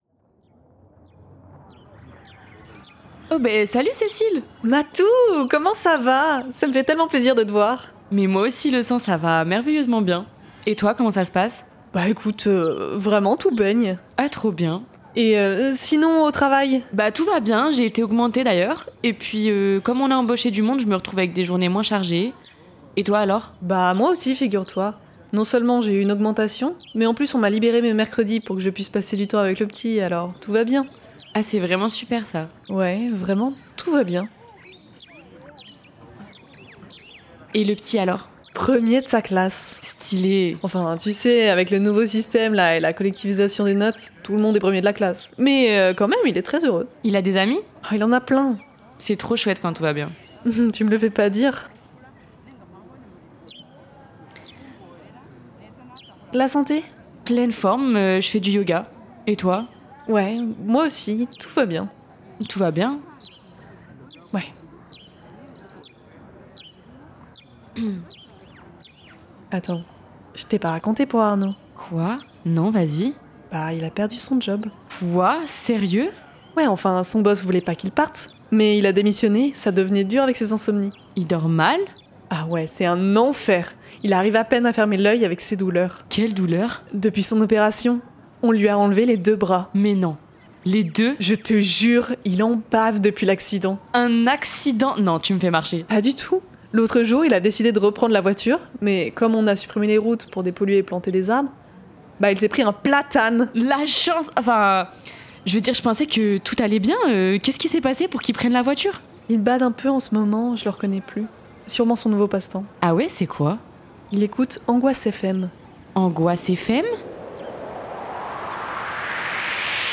Si vous êtes ici c’est pour entendre une partie de JDR horrifique…